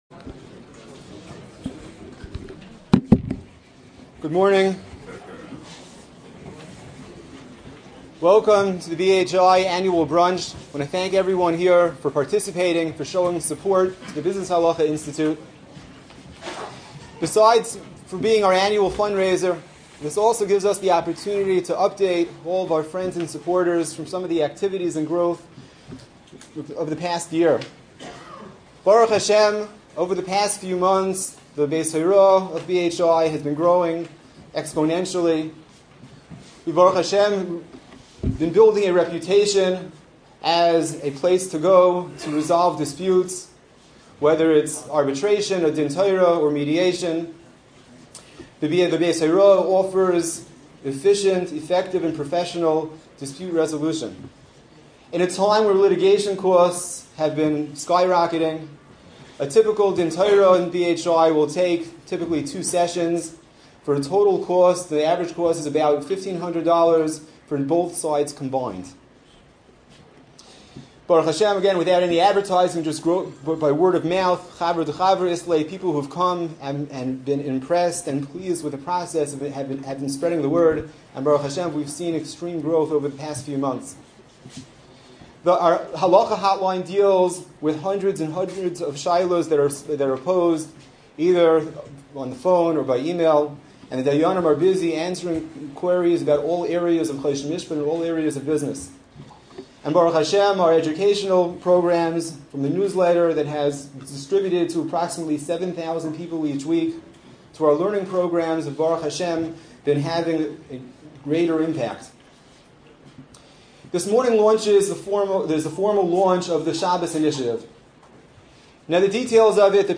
Panel Discusion